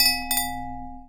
chime_bell_04.wav